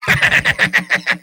Robot-filtered lines from MvM. This is an audio clip from the game Team Fortress 2 .
Soldier_mvm_laughhappy02.mp3